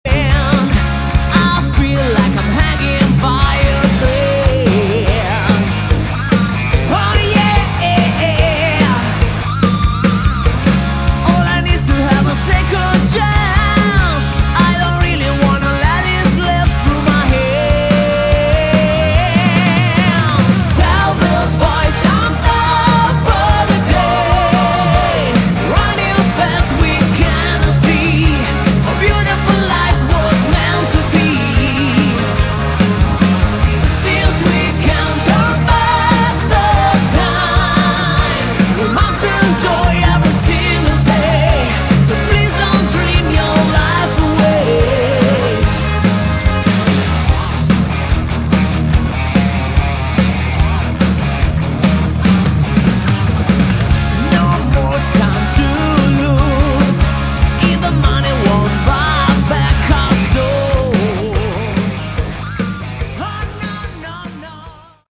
Category: Melodic Rock
vocals
guitars
keyboards
bass
drums